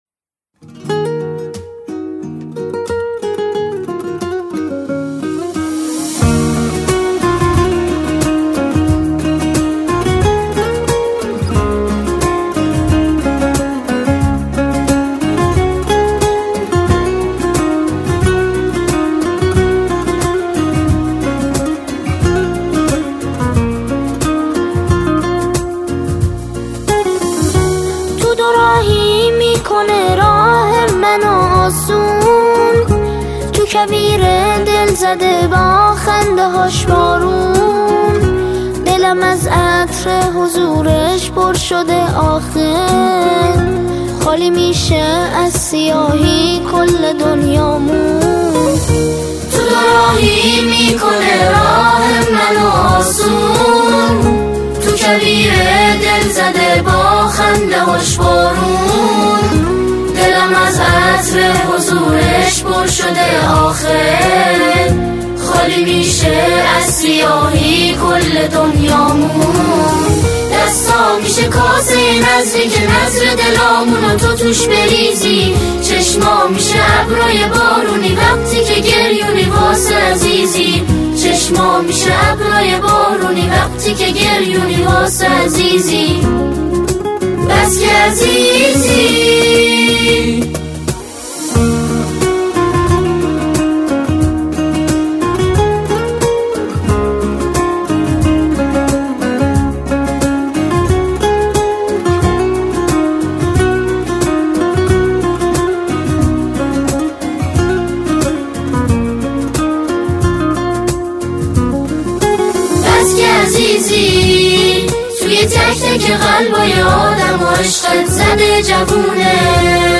سرودهای امام زمان (عج)
به صورت جمع خوانی